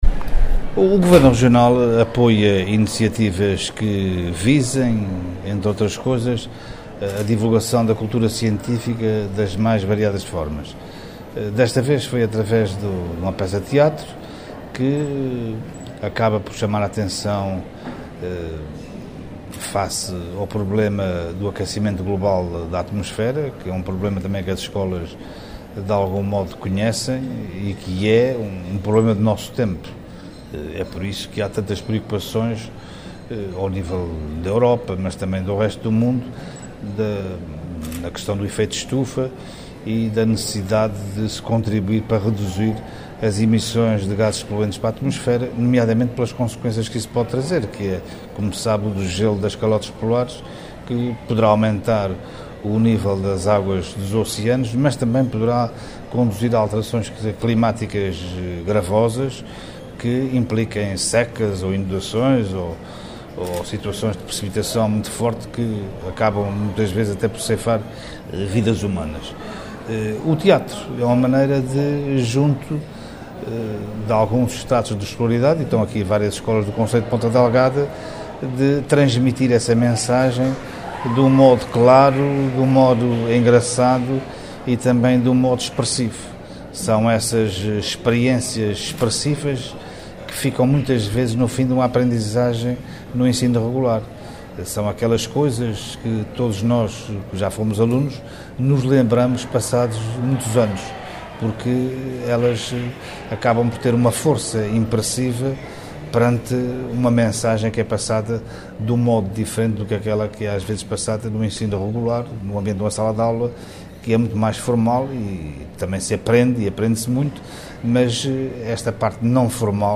José Contente, que falava na sessão de abertura do evento “Aquecimento esclarecido”, uma peça de teatro dedicada às questões do ambiente, dirigida aos jovens das escolas açorianas, sublinhou que o Governo dos Açores apoia este tipo de iniciativas porque “estão aliadas a um conteúdo científico, a questões ligadas ao aquecimento global e, neste caso concreto, alerta para o facto de que ainda há pessoas que não acreditam que o fenómeno do aquecimento global esteja a ocorrer”.